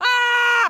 shout.mp3